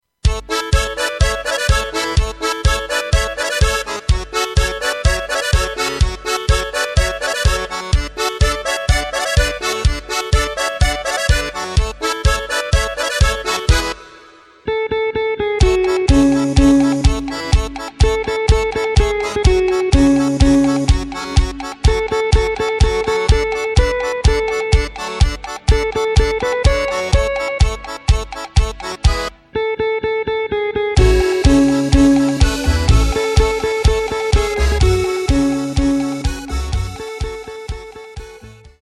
Takt:          4/4
Tempo:         125.00
Tonart:            C